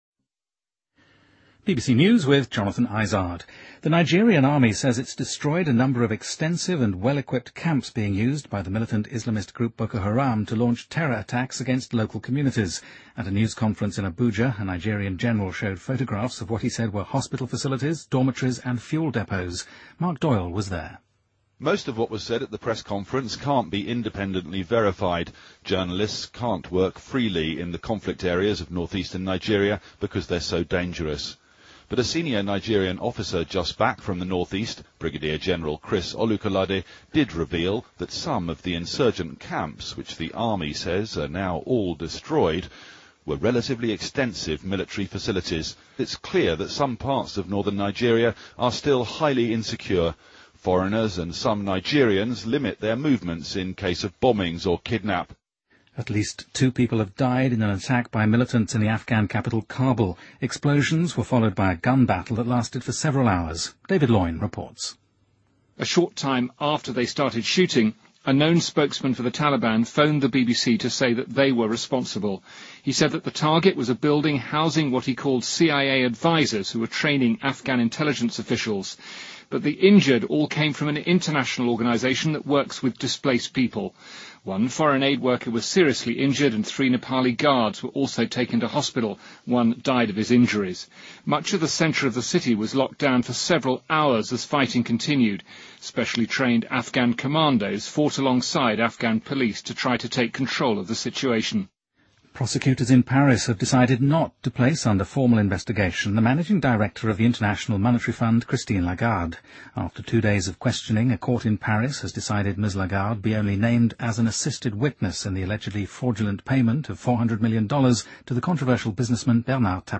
BBC news,2013-05-25